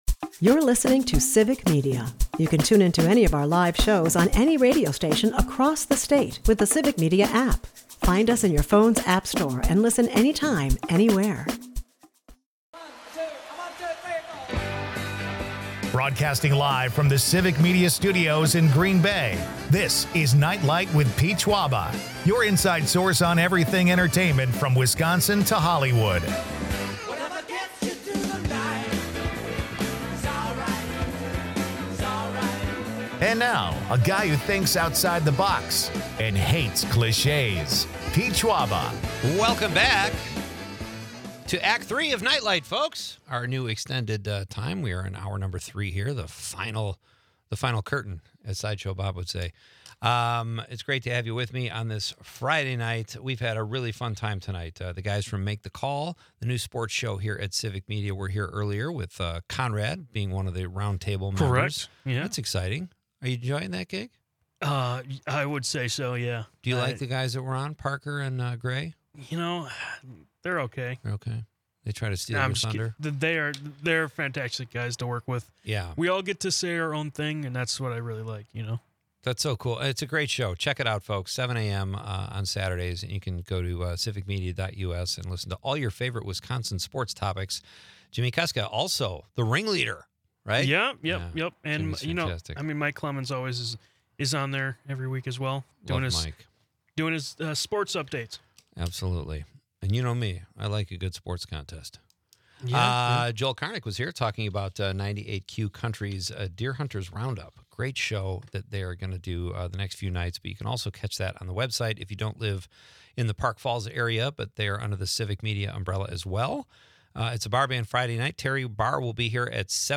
Broadcasting live from Green Bay